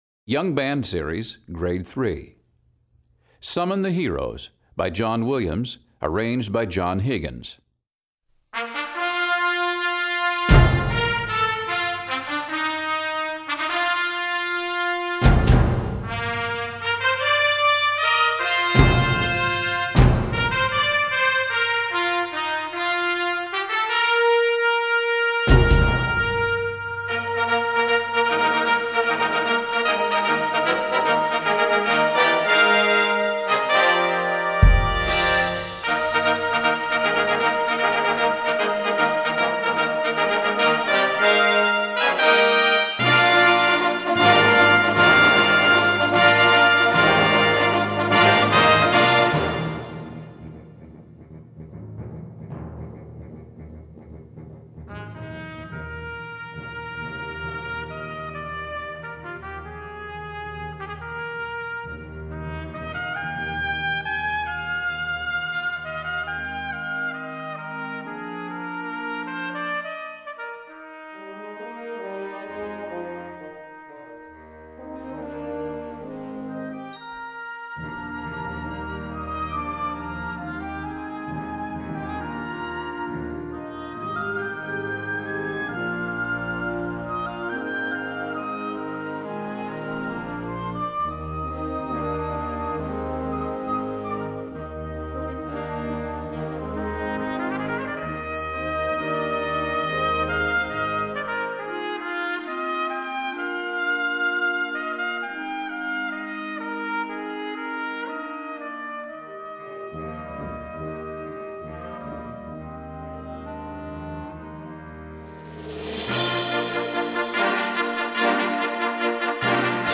Gattung: Konzertwerk
Besetzung: Blasorchester
das ein lyrisches Trompetensolo, beinhaltet.